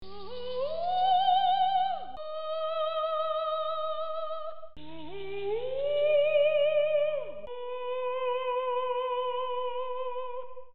Creepy Singing - Bouton d'effet sonore